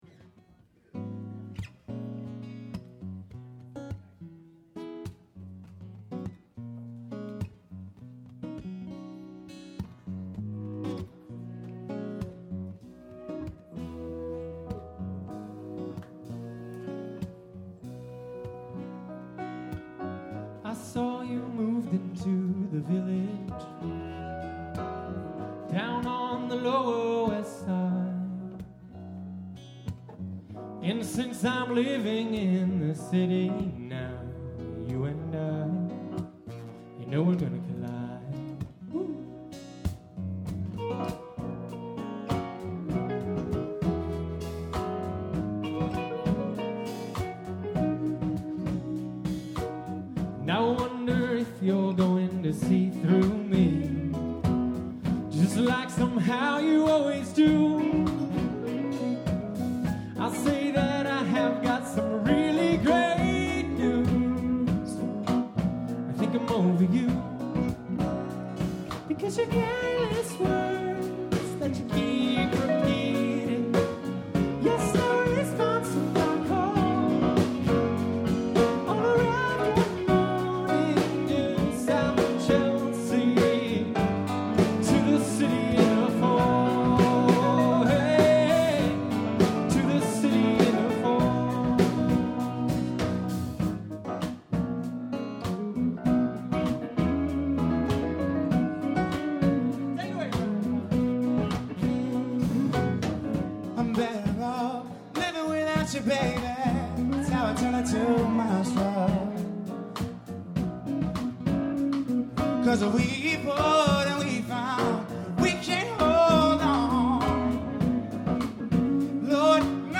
grand piano
drums
The two of them sang Motown-style, full of falsettos.
The voices are obvious, as are the drums.